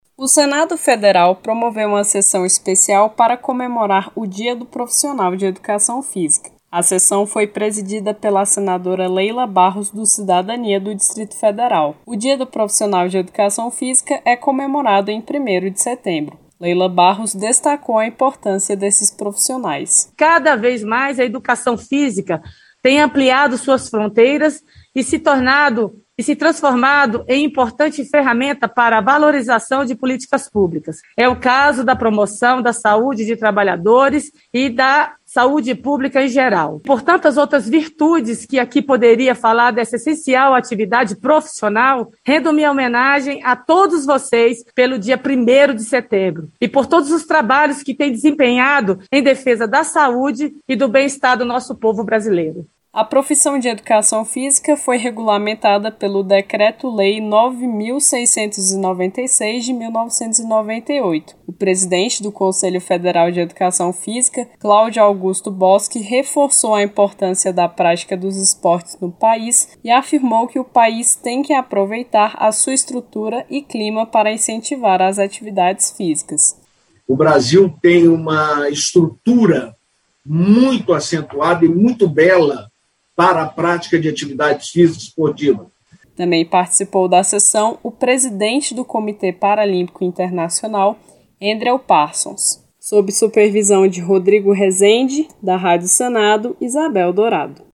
O Senado realizou nesta segunda-feira (13) Sessão Especial para homenagear os profissionais de Educação Física. A sessão foi proposta pela senadora Leila Barros (Cidadania-DF), que ressaltou a importância da categoria para o bem estar da sociedade.